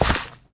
1.21.4 / assets / minecraft / sounds / step / gravel2.ogg
gravel2.ogg